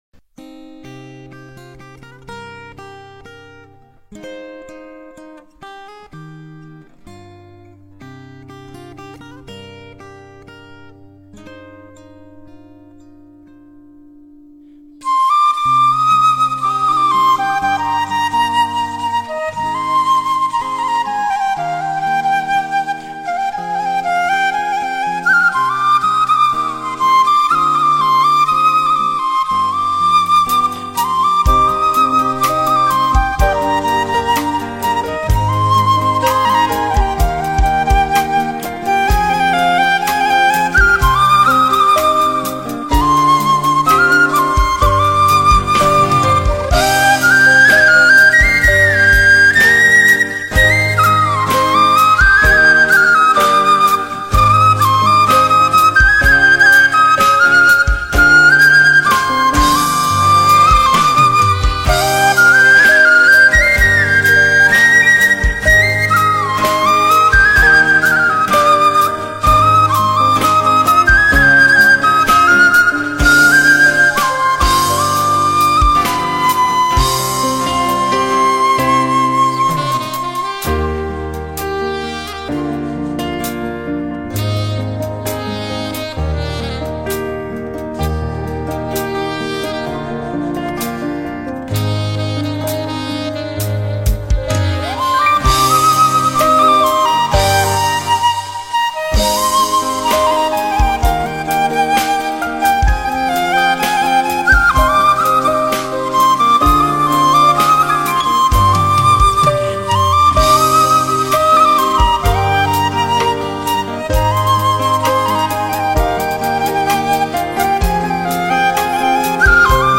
Nhạc Tiktok 3 lượt xem 20/03/2026